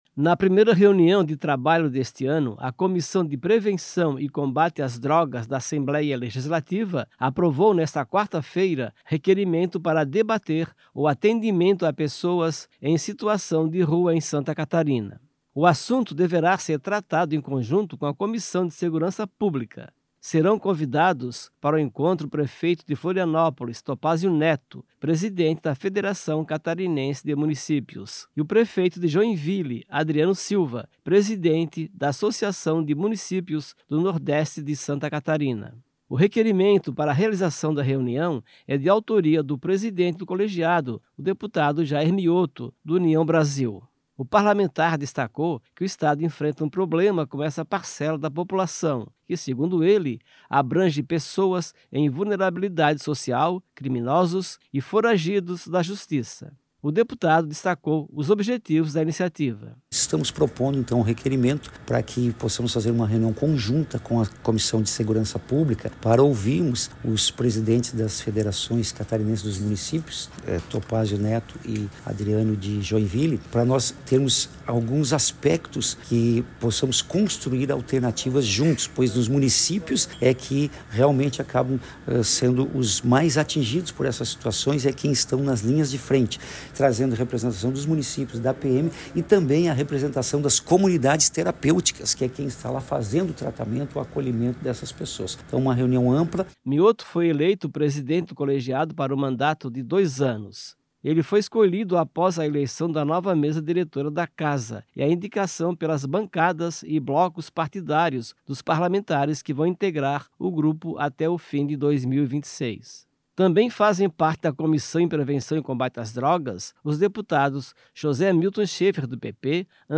Entrevista com:
- deputado Jair Miotto (União)